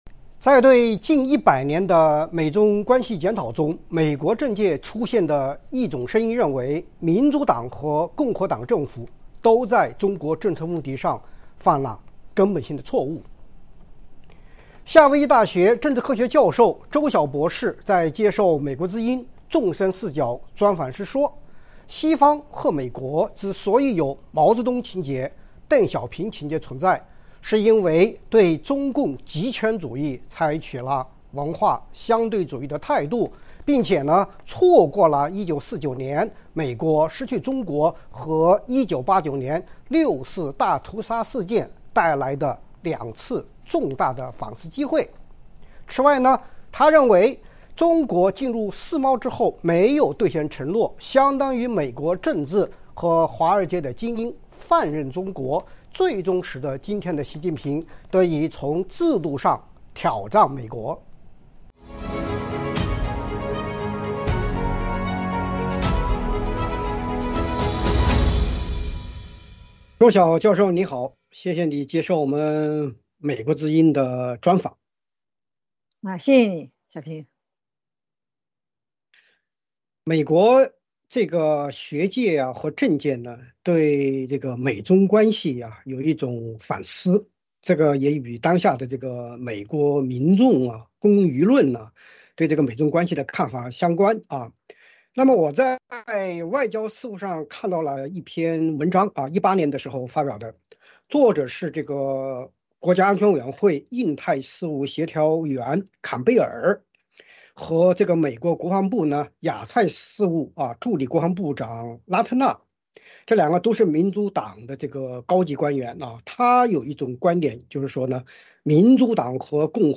《纵深视角》节目进行一系列人物专访，受访者发表的评论不代表美国之音的立场。